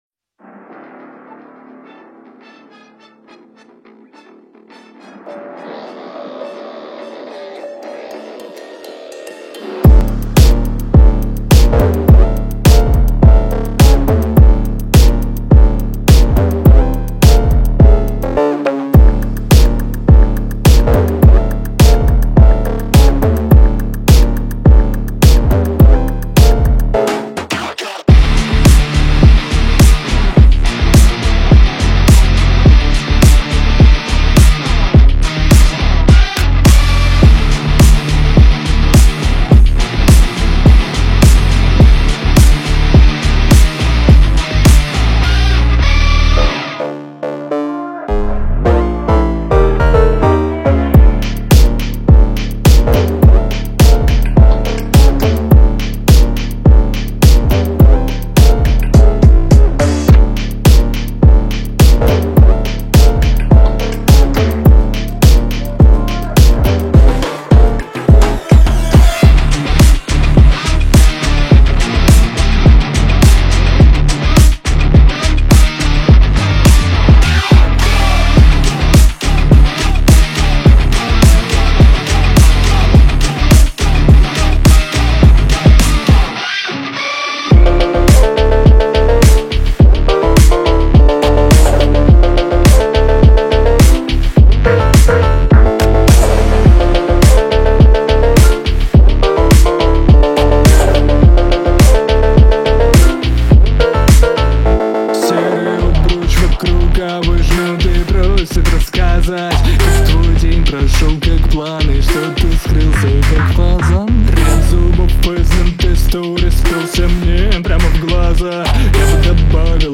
Hip Hop
Russian singer-songwriter